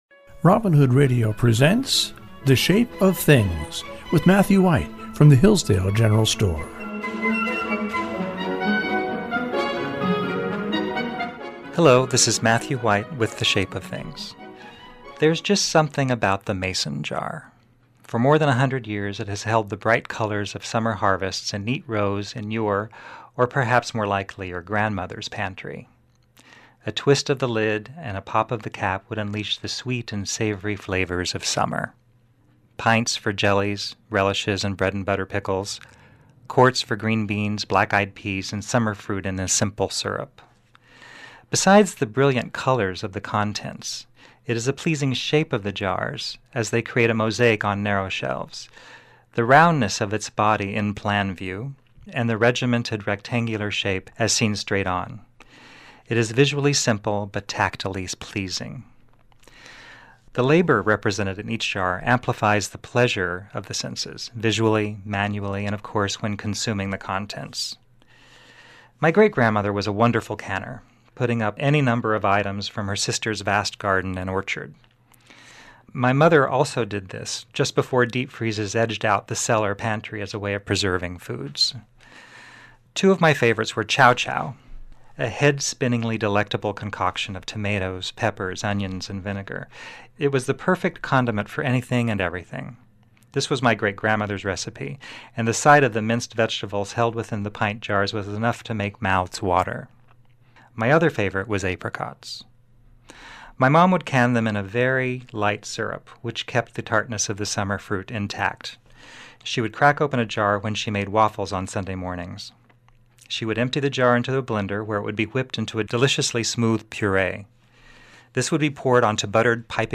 reads short stories he has written based on the region.